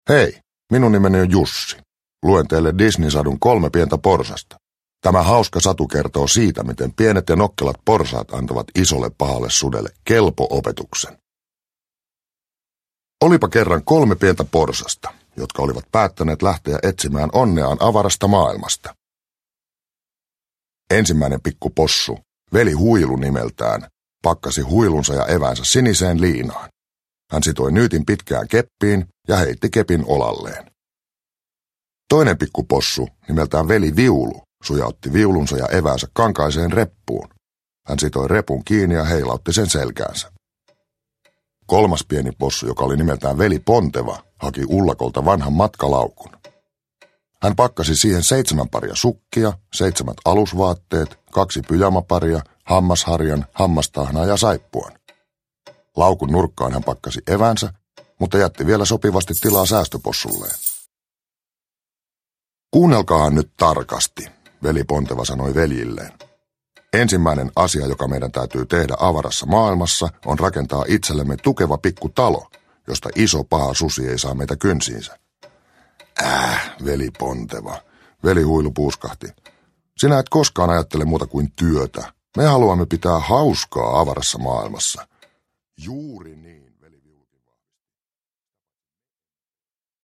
Kolme pientä porsasta – Ljudbok – Laddas ner